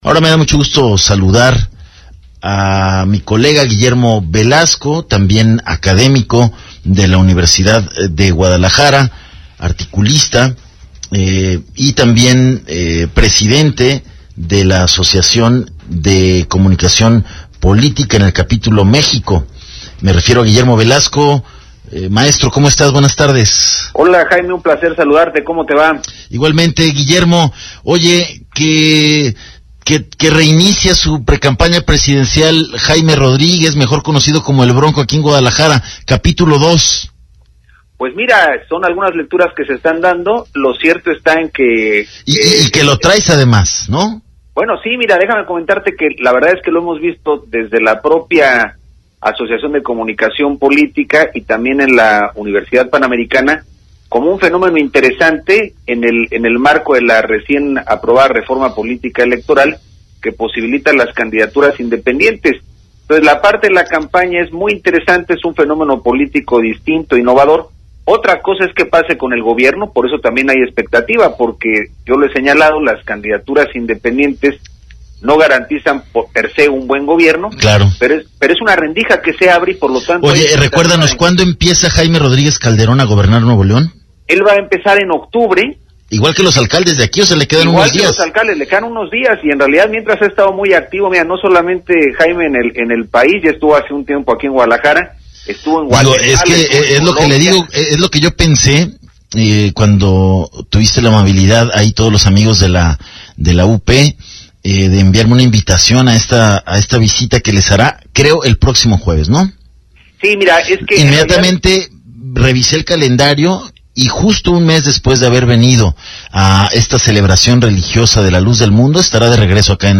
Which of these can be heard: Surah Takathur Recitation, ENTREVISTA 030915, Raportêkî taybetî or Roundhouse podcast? ENTREVISTA 030915